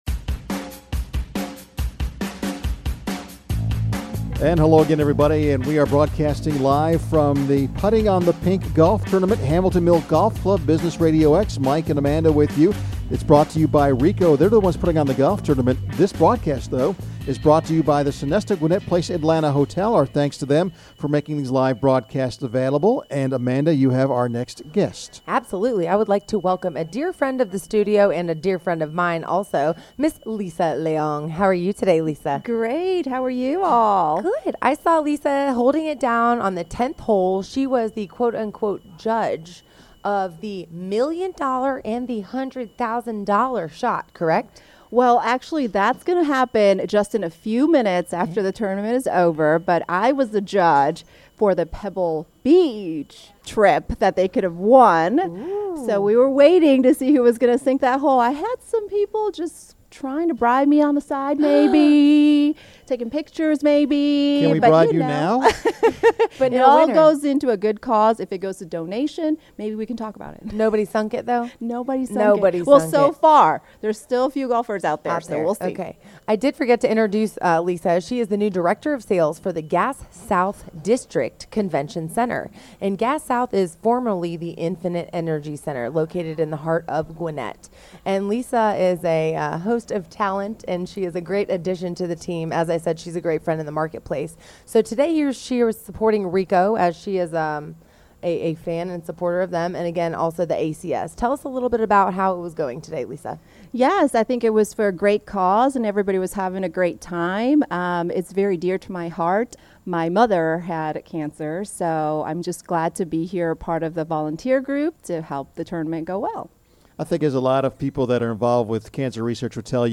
Thanks to a generous contribution by the Sonesta Gwinnett Place Atlanta Hotel, Business RadioX was on site again this year to broadcast live and conduct interviews with event organizers, corporate sponsors, and golfers.